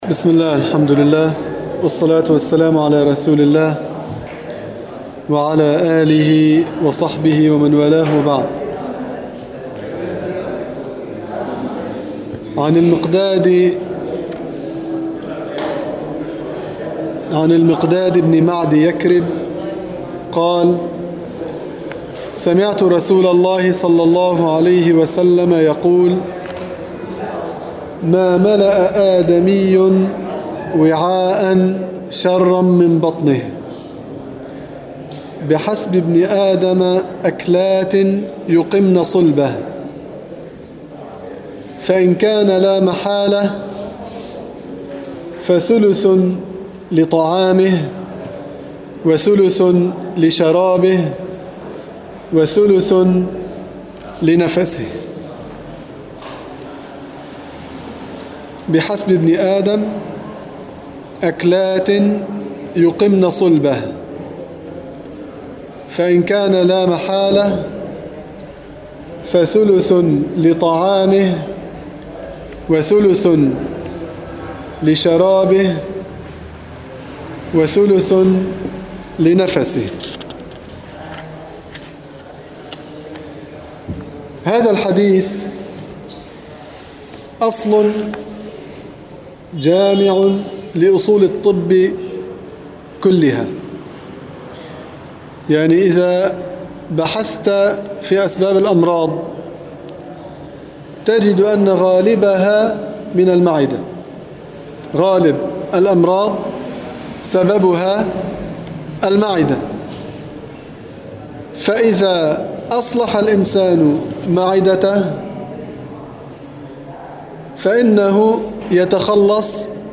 دروس تفسير الحديث
في مسجد القلمون الغربي